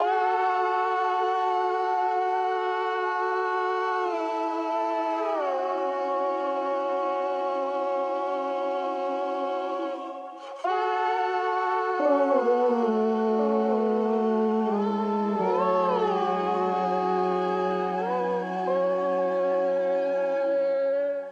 vox2